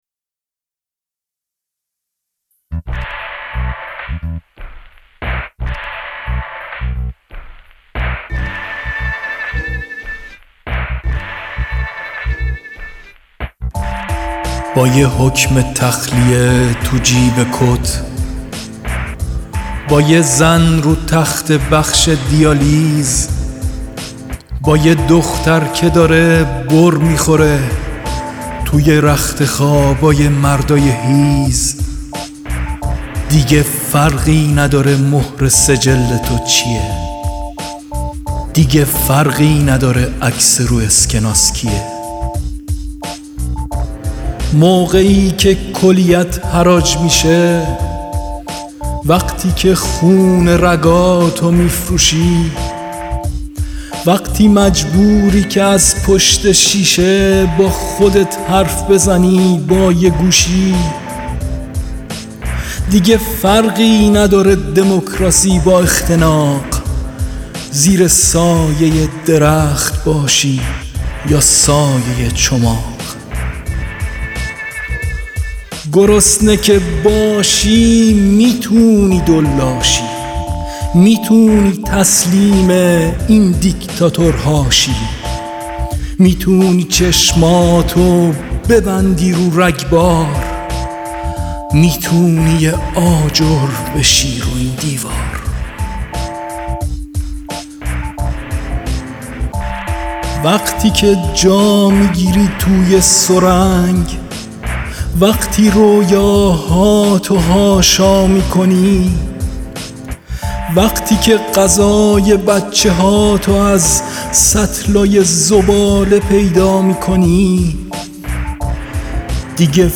دانلود دکلمه گرسنه که باشی با صدای یغما گلرویی
اطلاعات دکلمه